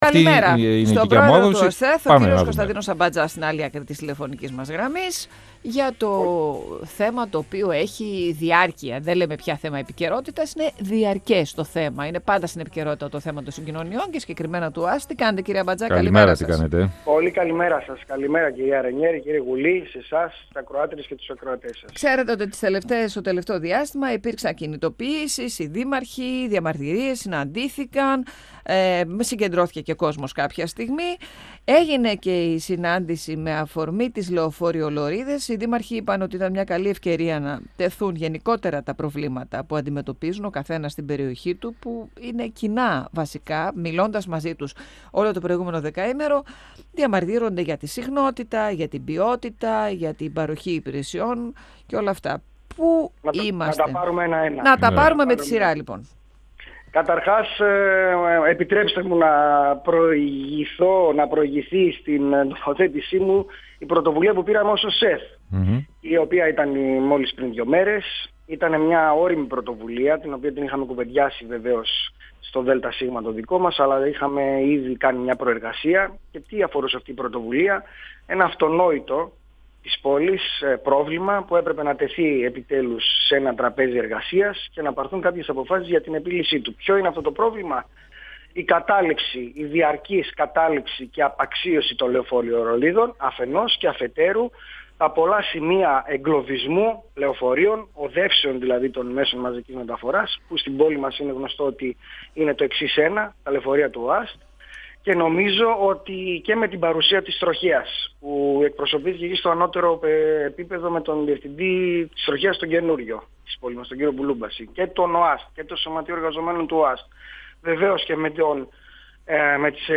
Ο πρόεδρος του ΟΣΕΘ, Κώστας Αμπατζάς, στον 102FM του Ρ.Σ.Μ. της ΕΡΤ3 Σε νέες παρεμβάσεις για τη βελτίωση της αστικής συγκοινωνίας στη Θεσσαλονίκη, πρόκειται να προχωρήσουν οι αρμόδιοι στο επόμενο διάστημα.
Συνεντεύξεις